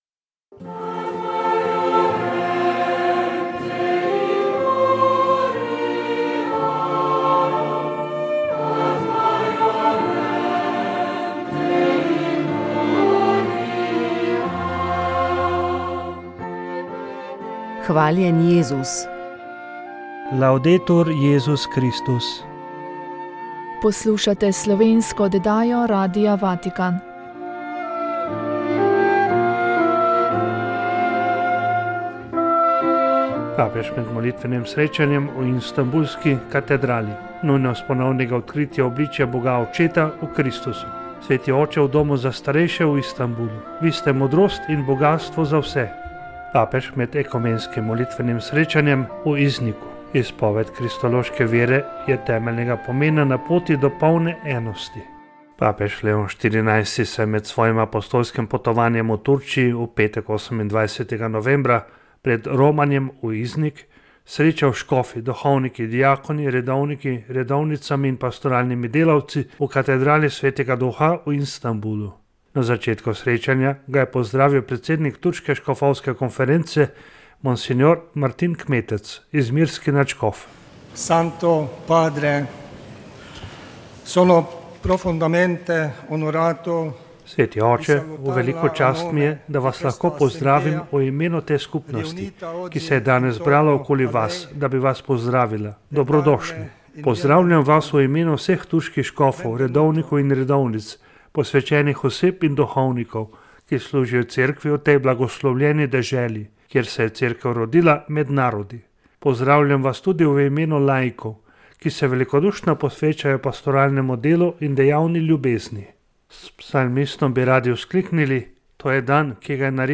Naša želja je bila odpreti prostor za iskren in spoštljiv pogovor med teisti in ateisti. Zadnja oddaja povzema ključna spoznanja, ki jih je prinesel dialog, besedo pa je imelo tudi občinstvo, ki se je zbralo ob snemanju oddaje.